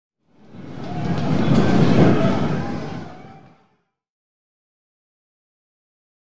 Players, Fast Run By In Hall, Walla.